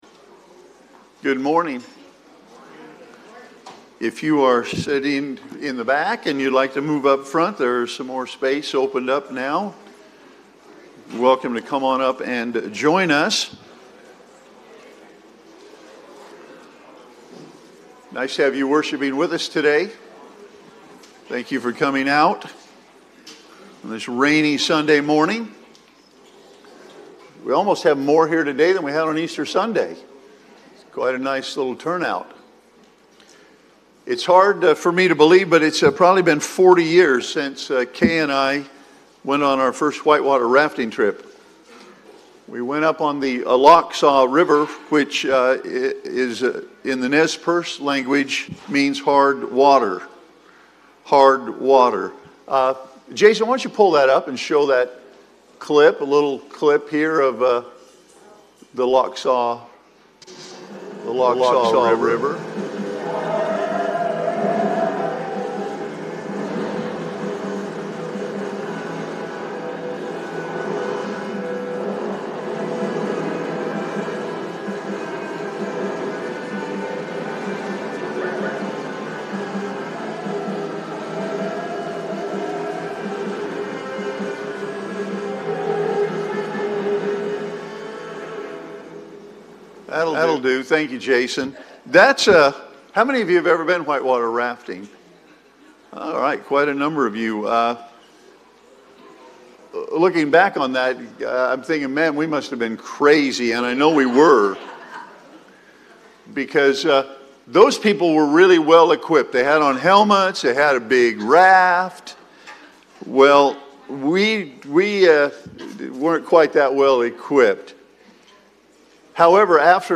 4-12-Sermon-Audio.mp3